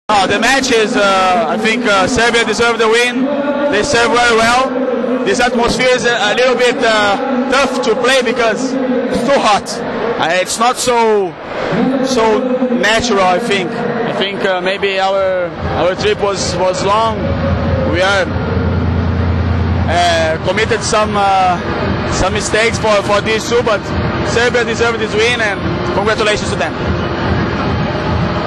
IZJAVA BRUNA REZENDEA